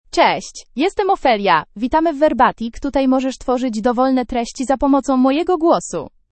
Ophelia — Female Polish (Poland) AI Voice | TTS, Voice Cloning & Video | Verbatik AI
OpheliaFemale Polish AI voice
Ophelia is a female AI voice for Polish (Poland).
Voice sample
Listen to Ophelia's female Polish voice.
Ophelia delivers clear pronunciation with authentic Poland Polish intonation, making your content sound professionally produced.